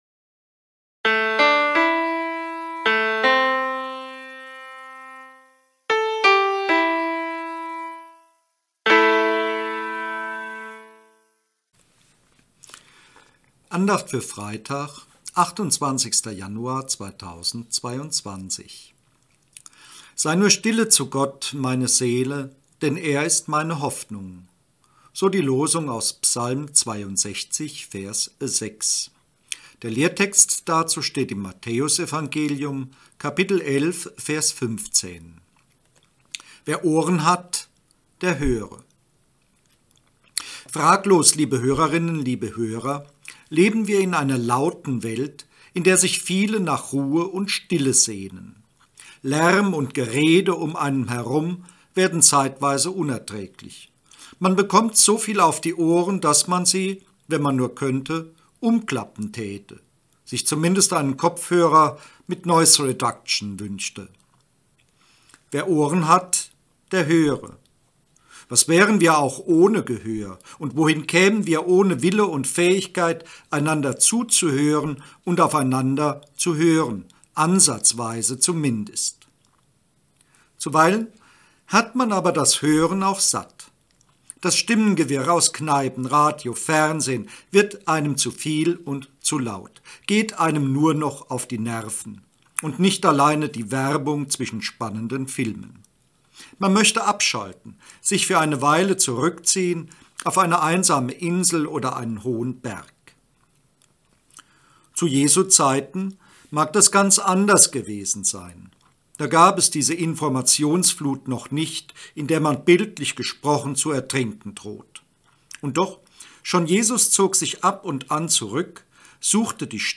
Losungsandacht für Freitag, 28.01.2022